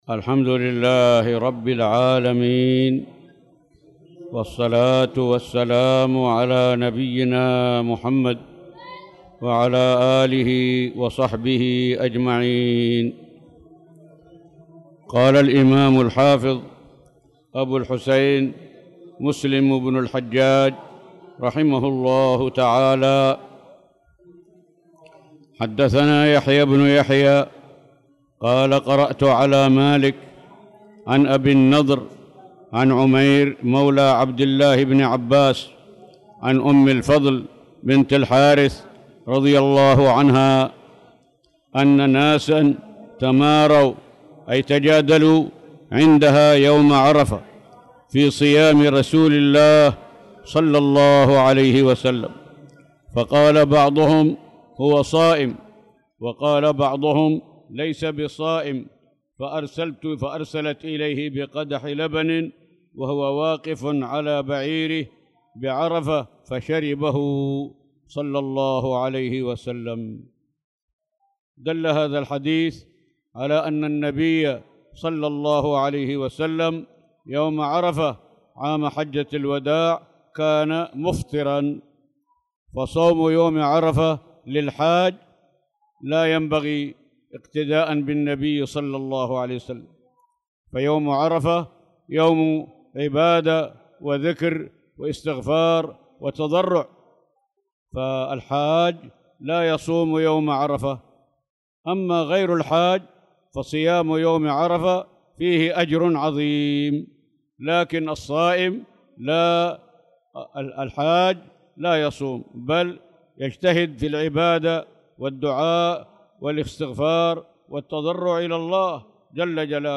تاريخ النشر ١٢ ذو القعدة ١٤٣٧ هـ المكان: المسجد الحرام الشيخ